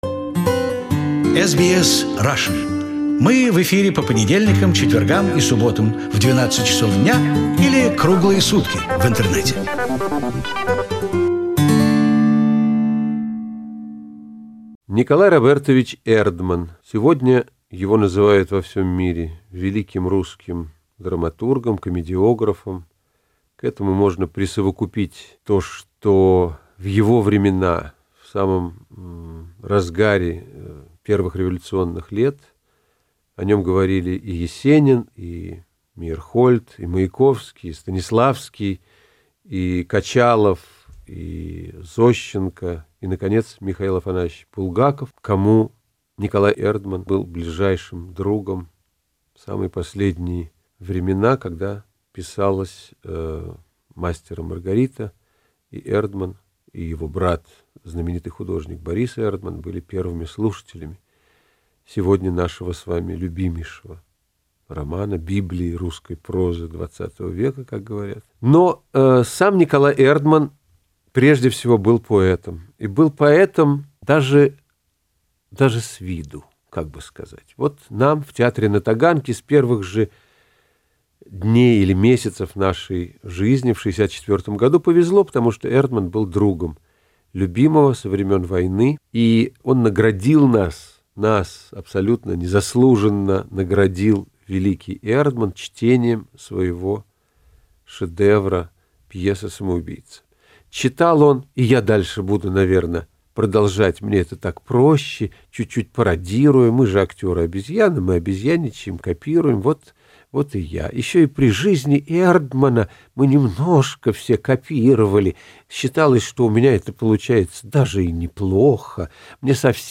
His concerts were a big success in Australia, and we asked him to record some of his material for the radio. Fortunately the recordings survived and we are happy to preserve them on our web archives In this recording he speaks about repressed Soviet playwright Nikolai Erdman, who was known for his quirky satire and humour